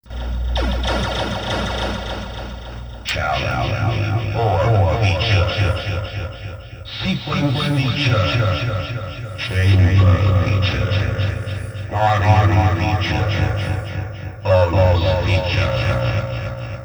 Dieses Board bietet eine Einstellmöglichkeit für die Intensität vom Echo und Delay.
Hier ein Klangbeispiel im Centaur: